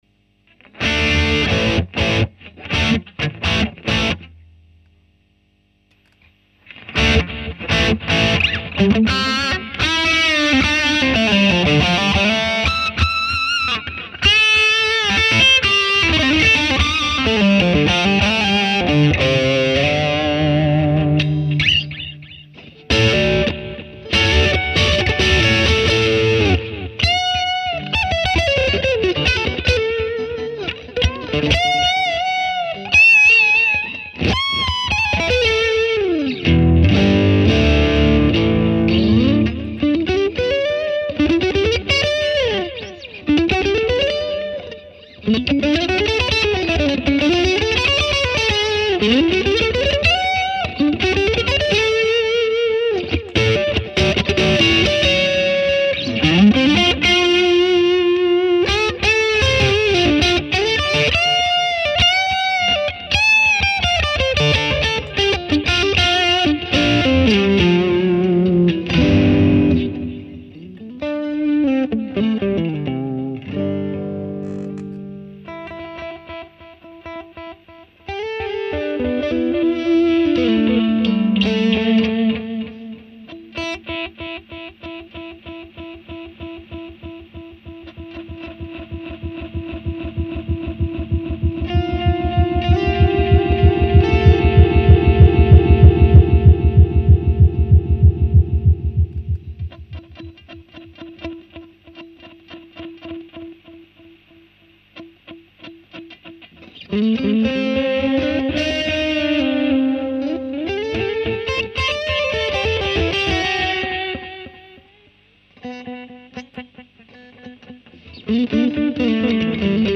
Et enfin, le mythe, le memory man ( même pas deluxe ! ce qui les rend plus quotés ! ), célèbre delay/chorus analogique ..
Petit sample, pardon pour la hausse de volume sur la fin, désolé pour vos oreilles et attention aux enceintes !